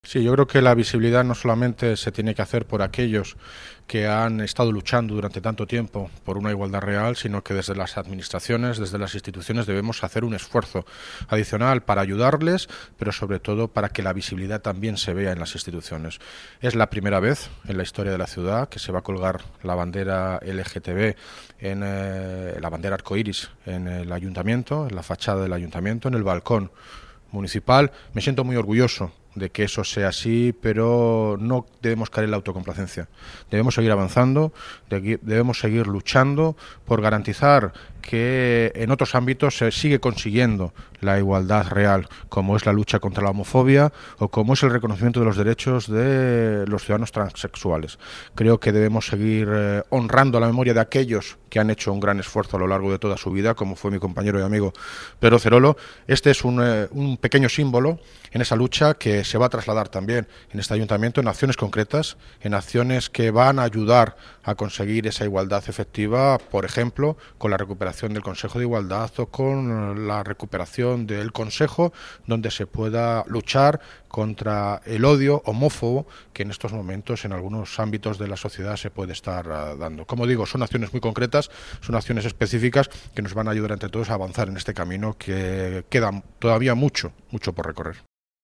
Audio - David Lucas (Alcalde de Móstoles) Sobre conmemoración el Día Internacional del Orgullo LGTB